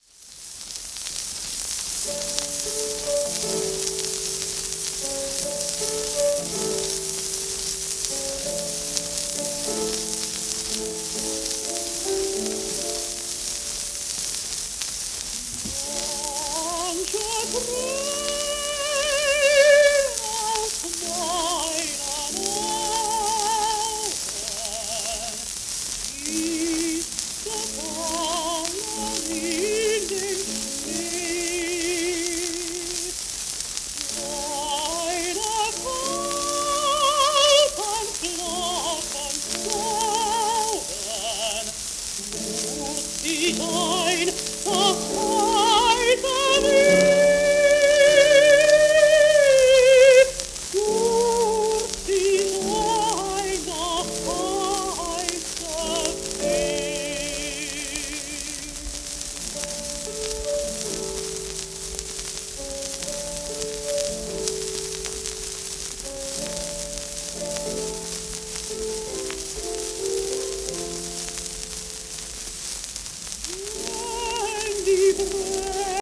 エレナ・ゲルハルト(Ms:1883-1961)
w/C.V.ボス(P)
シェルマン アートワークスのSPレコード